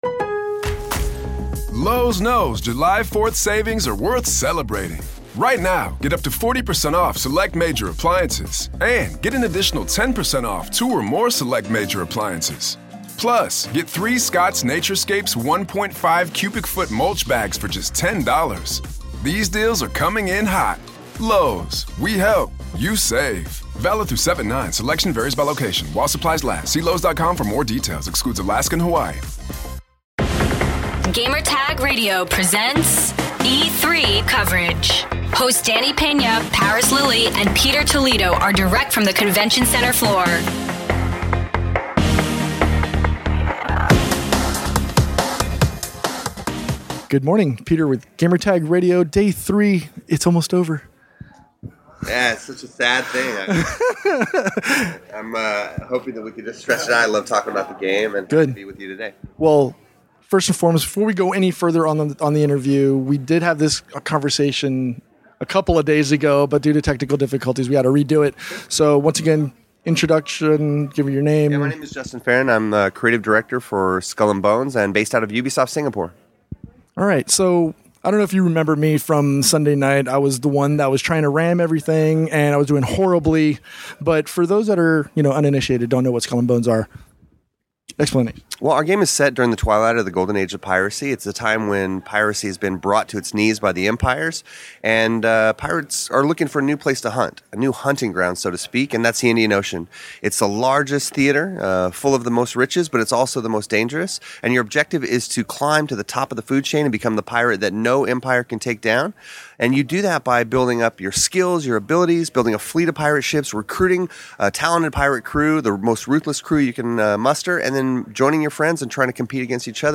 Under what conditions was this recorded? E3 2018: Skull & Bones Interview